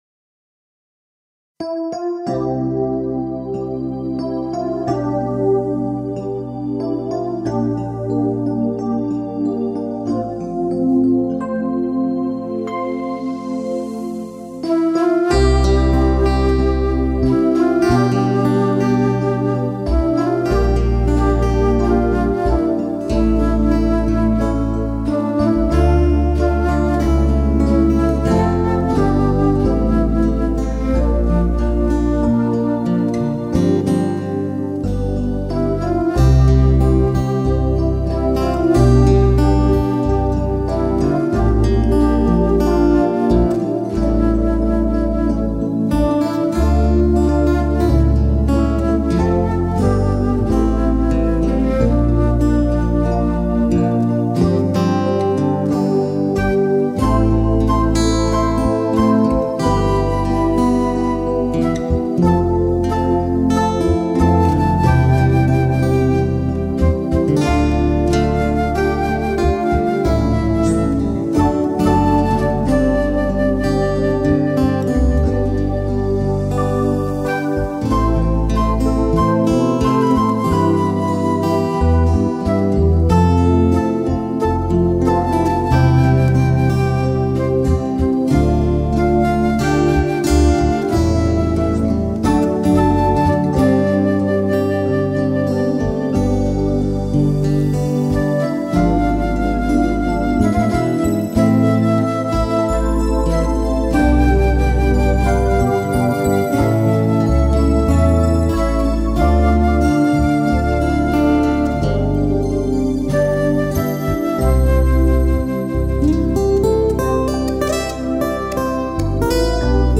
26. März 2024 | 19.30h Impulsvortrag in der Karwoche